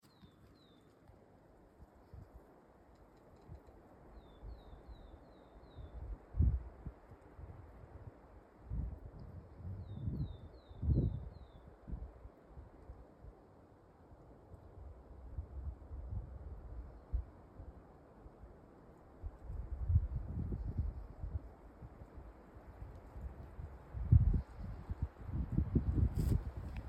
Willow Tit, Poecile montanus
Administratīvā teritorijaLimbažu novads
StatusSinging male in breeding season